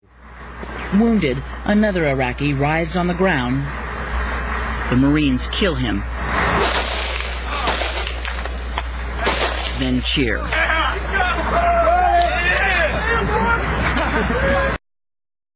Then cheer.